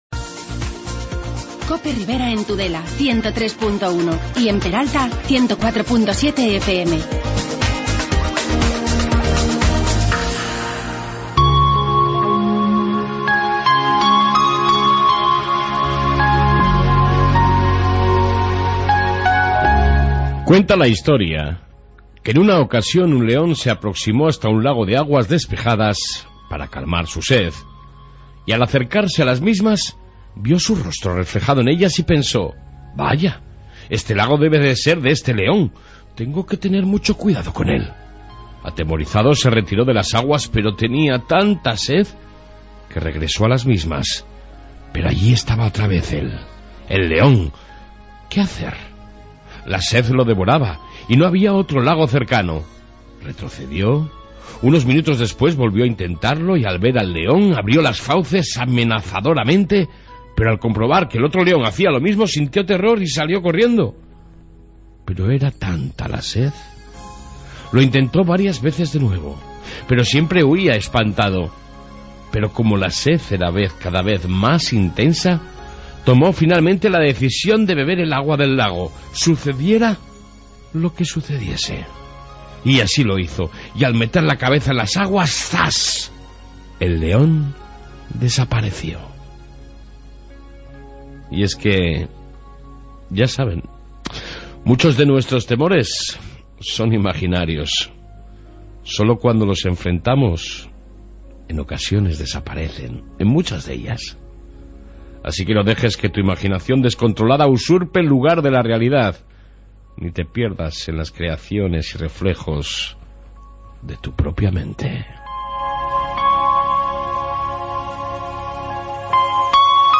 AUDIO: Reflexión Matutina, informe Policia municipal, entrevista con el Ex-Alcalde de Funes por las Amenazas recibidas y otras noticias de...